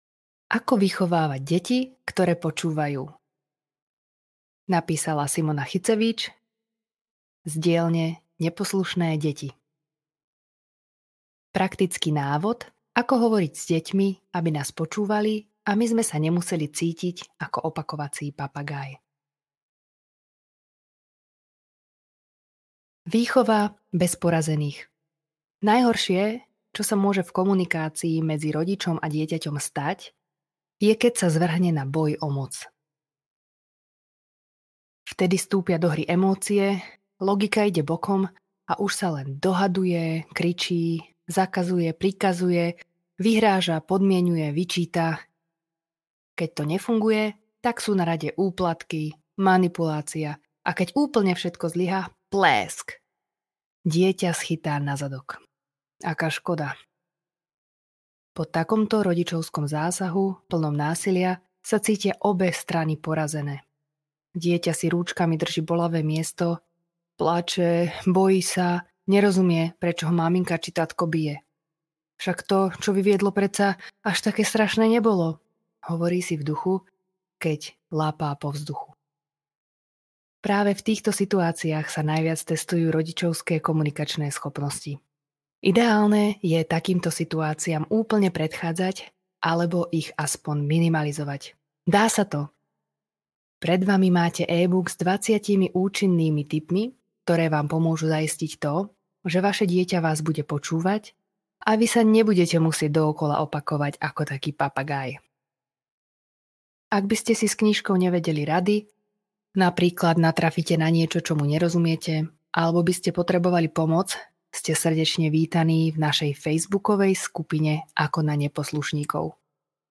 Ako vychovávať deti, ktoré počúvajú (Audio Kniha)